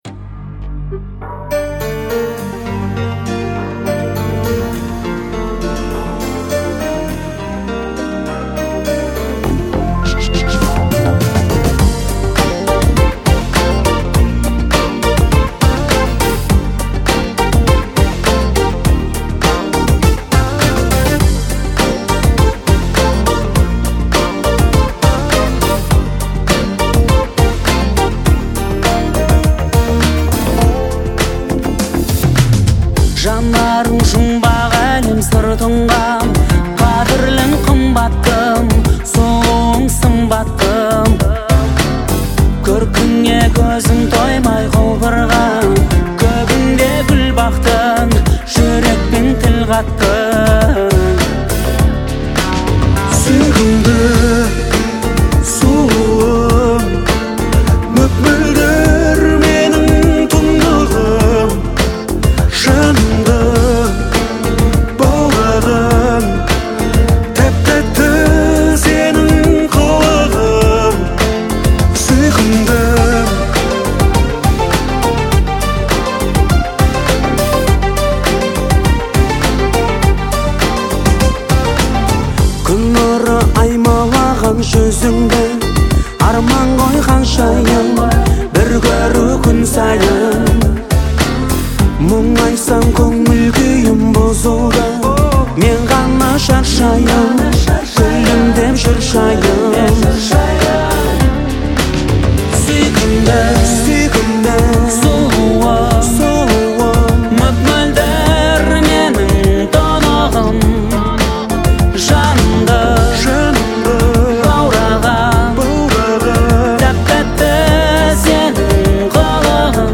романтическая песня